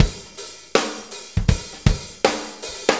Shuffle Loop 23-12.wav